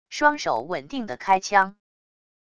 双手稳定的开枪wav音频